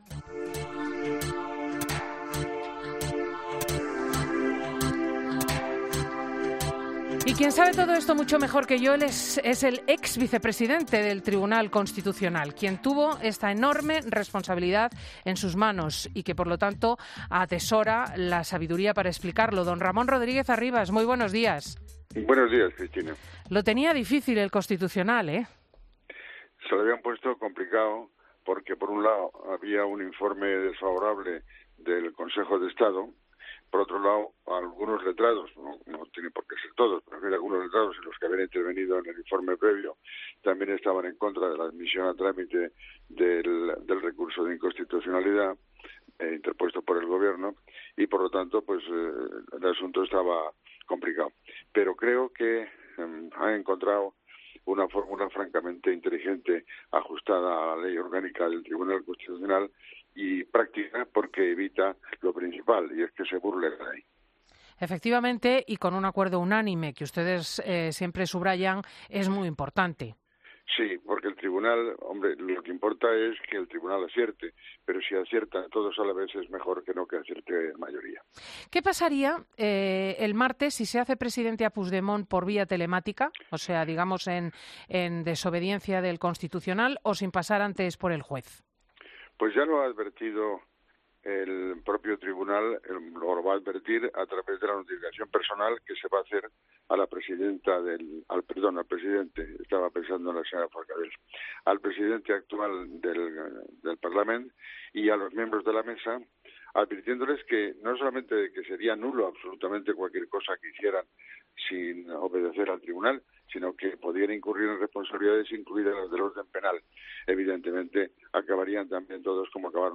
Ramón Rodríguez Arribas, abogado y exvicepresidente del Tribunal Constitucional, ha estado en 'Fin de Semana' con Cristina López Schlichting y ha asegurado que al TC "se lo habían puesto complicado porque había un informe desfavorable del Consejo de Estado, y algunos letrados también estaban en contra del recurso, pero creo que han encontrado una fórmula francamente inteligente y práctica, evita lo principal que es que se burle la ley".